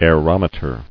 [aer·om·e·ter]